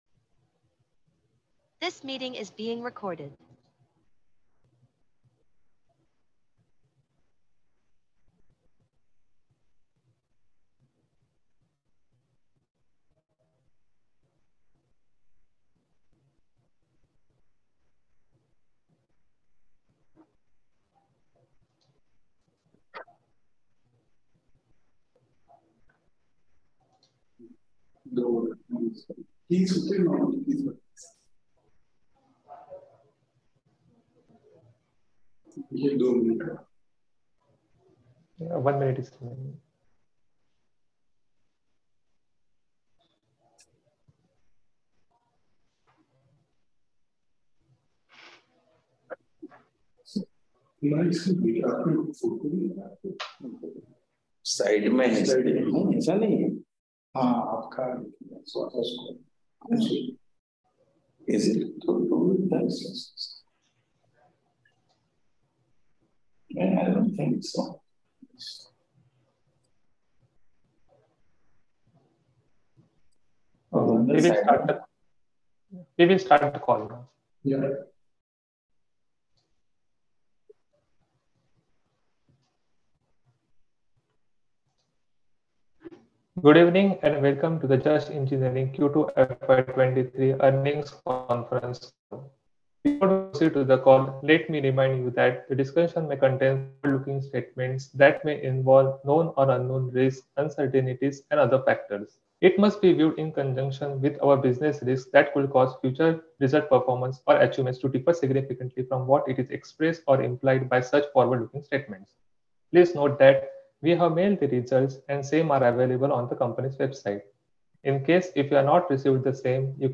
Earning conference call – 14Nov2022